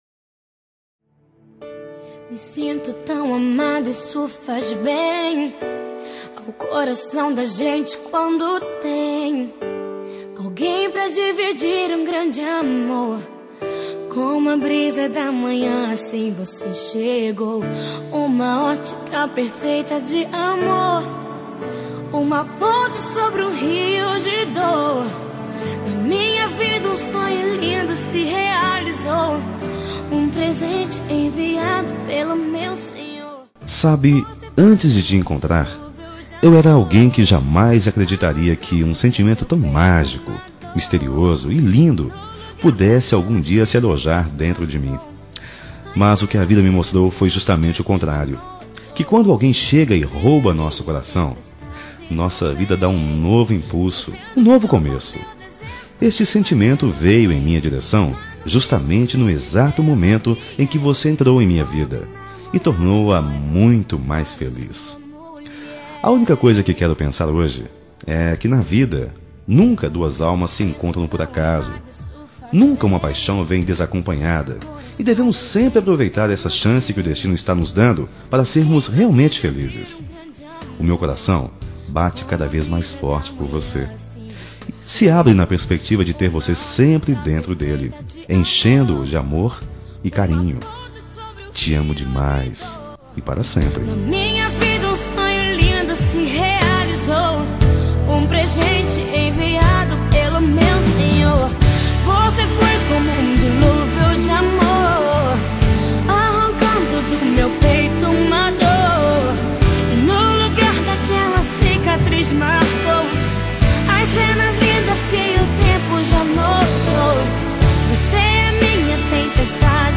Telemensagem Romântica Gospel – Voz Masculina – Cód: 5244 – Linda.